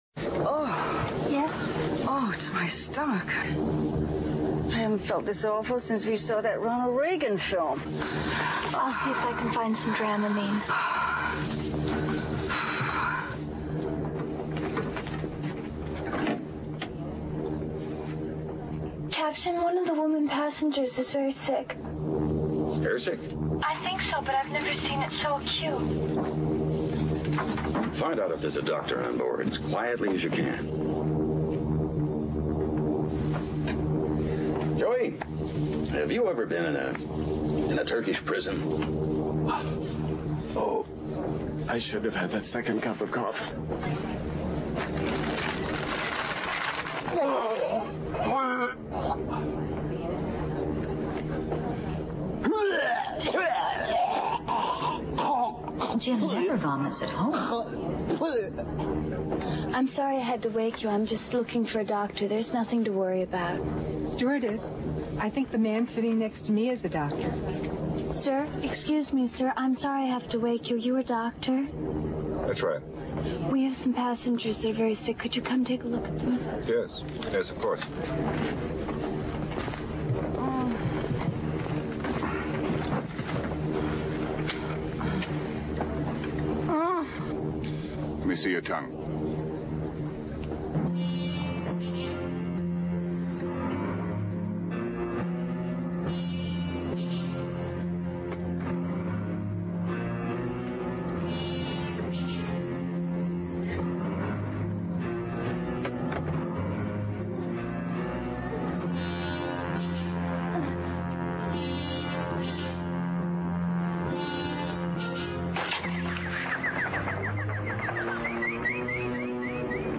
在线英语听力室影视剧中的职场美语 第114期:在飞机上的听力文件下载,《影视中的职场美语》收录了工作沟通，办公室生活，商务贸易等方面的情景对话。每期除了精彩的影视剧对白，还附有主题句型。